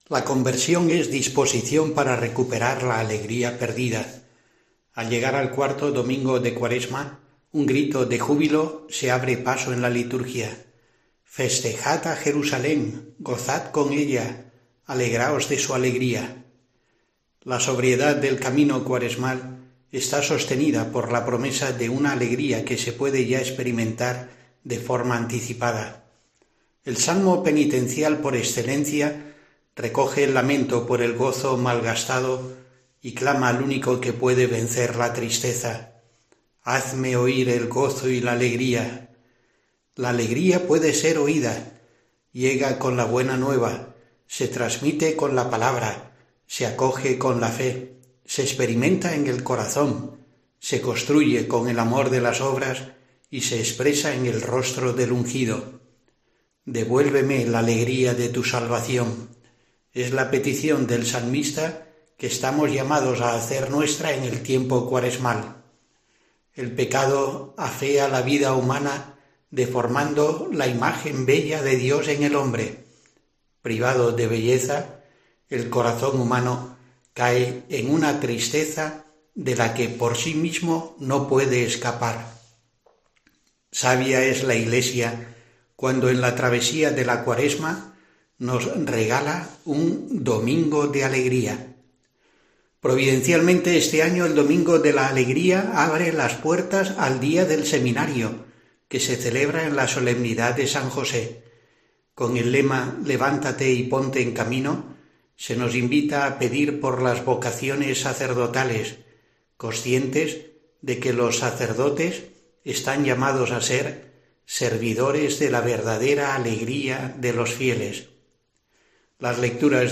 Monseñor José Rico Pavés, obispo de Asidonia-Jerez, profundiza en el sentido del cuarto domingo de Cuaresma en reflexión semanal de este viernes